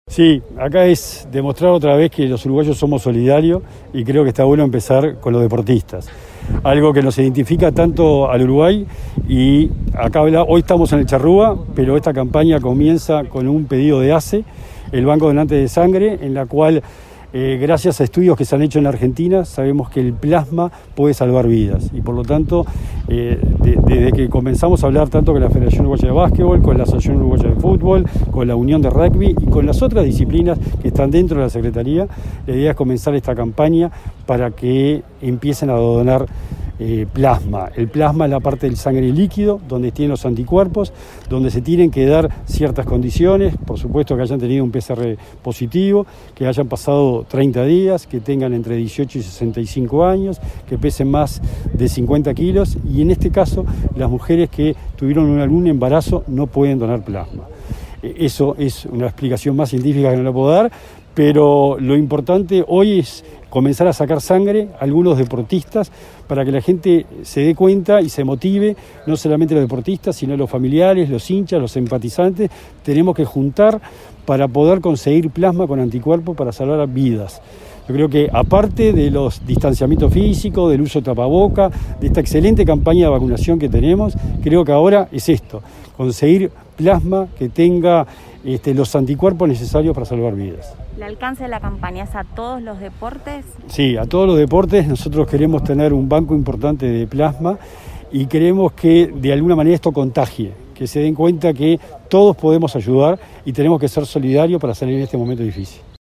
Declaraciones del titular de la Secretaría del Deporte, Sebastián Bauzá